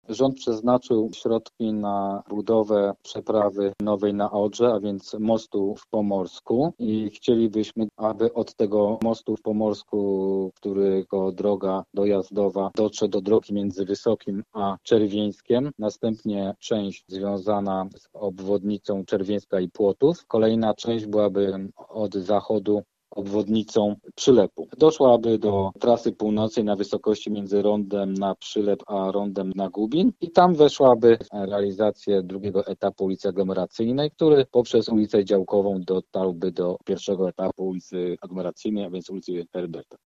Wiceprezydent Krzysztof Kaliszuk w rozmowie z RZG wyjaśnił jak ma przebiegać obwodnica Zachodnia: